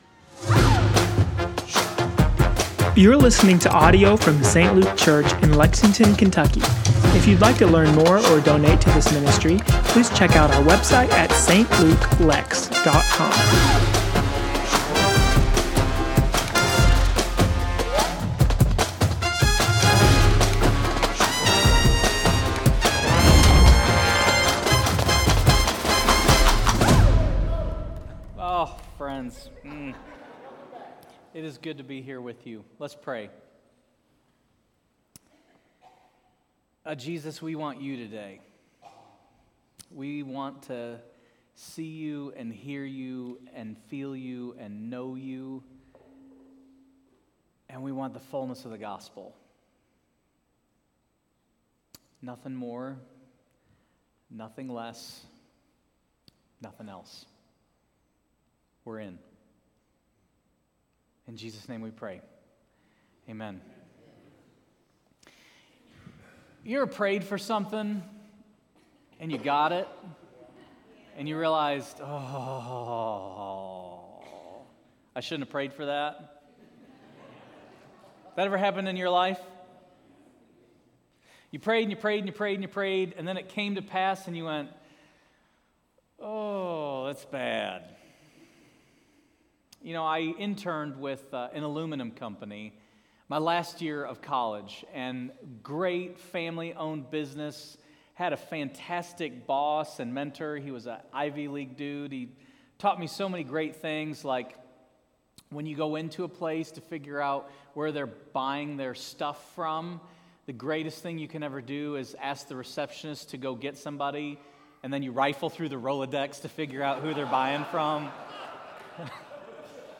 Sermons & Teachings